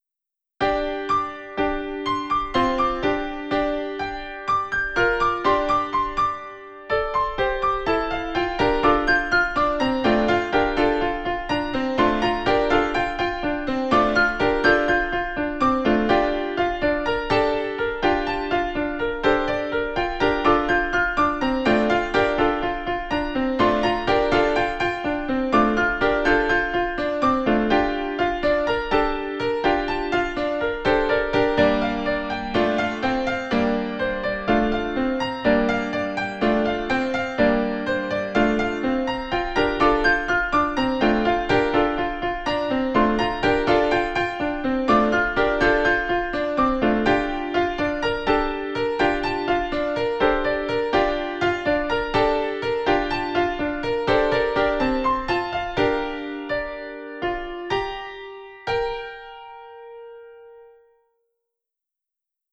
PIANO Q-S (31)